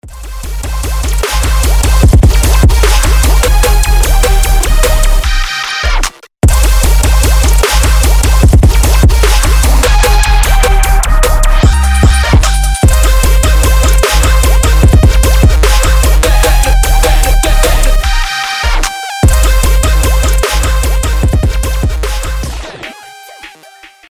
• Качество: 320, Stereo
громкие
электронная музыка
Electronica
Стиль: trap